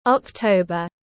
Tháng 10 tiếng anh gọi là october, phiên âm tiếng anh đọc là /ɒkˈtəʊ.bər/